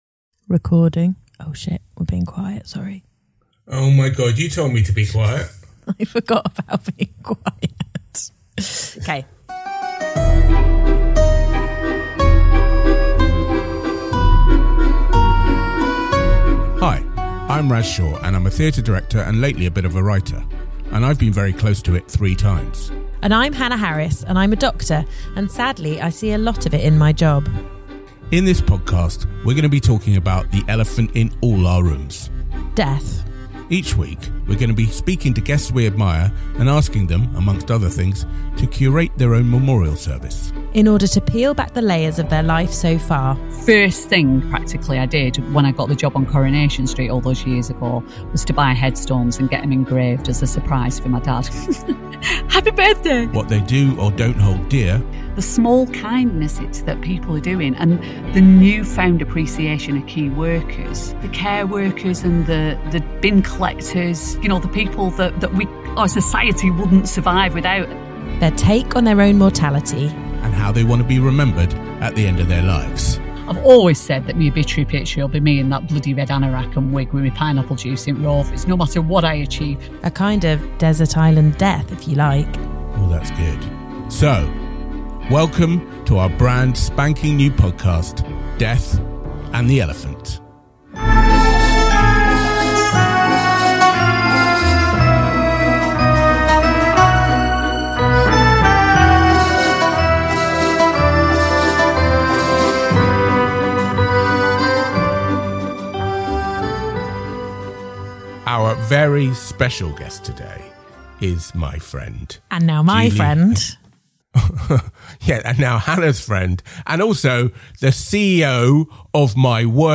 special guest, actress and all-round wonder woman, Julie Hesmondhalgh. They discuss life, theatre, activism, and her legacy as Hayley Cropper in Coronation Street.